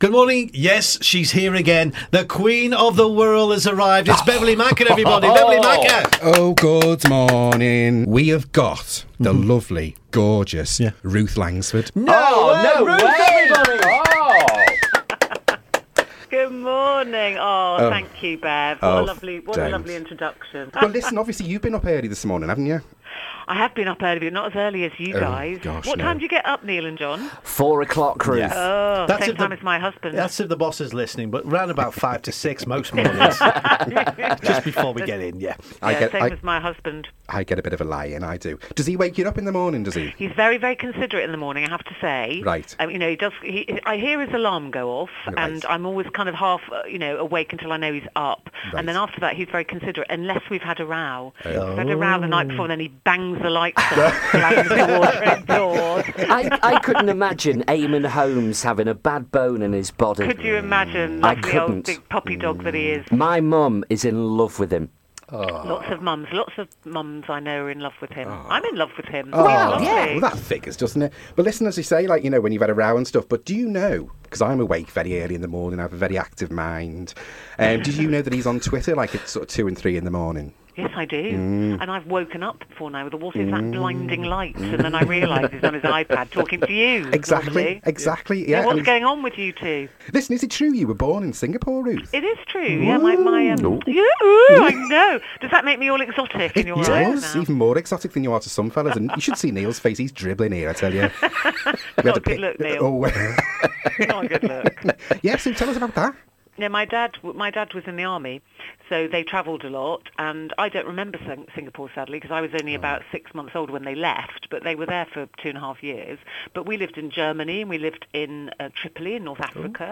part of the Wirral Radio Breakfast Show.